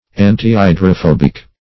Search Result for " antihydrophobic" : The Collaborative International Dictionary of English v.0.48: Antihydrophobic \An`ti*hy`dro*phob"ic\, a. (Med.)